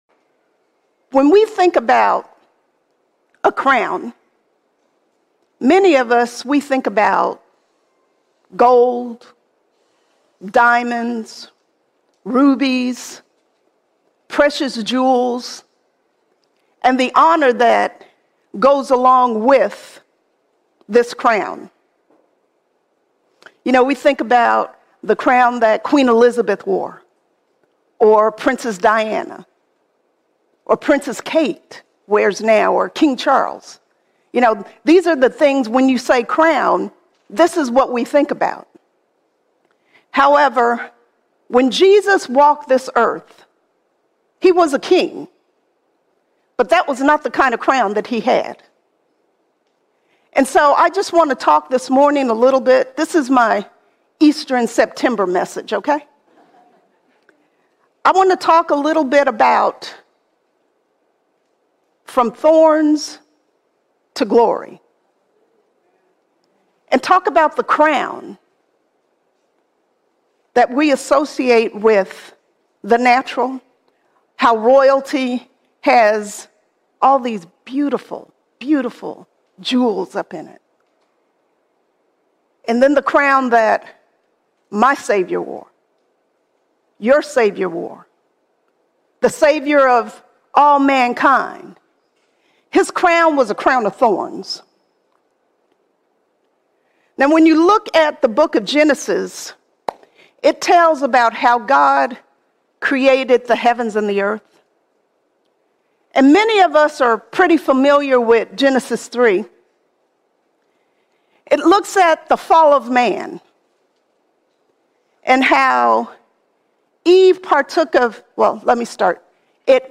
22 September 2025 Series: Sunday Sermons All Sermons From Thorns to Glory From Thorns to Glory From the fall came thorns, but in Christ we find a living hope!